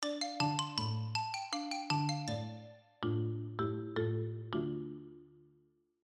Plays long end of the track